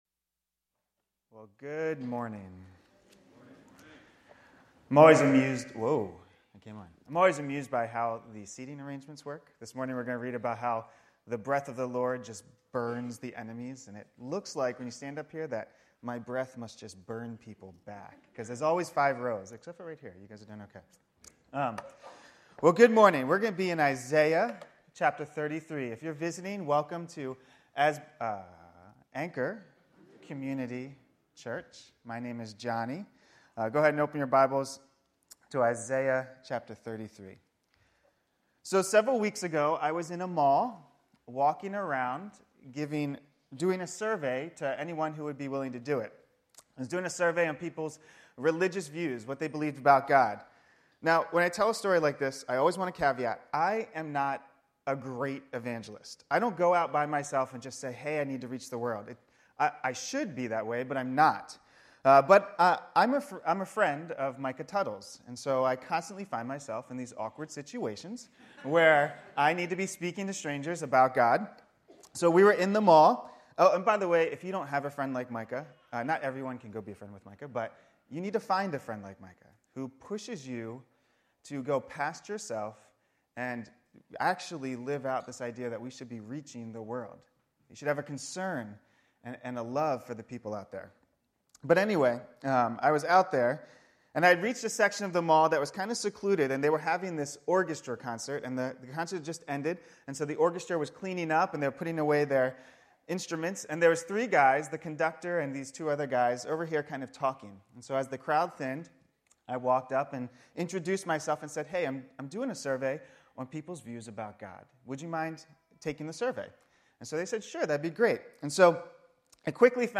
Sermons | Anchor Community Church